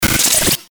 FX-1037-BREAKER
FX-1037-BREAKER.mp3